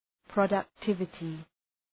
Προφορά
{,prəʋdək’tıvətı} (Ουσιαστικό) ● παραγωγικότητα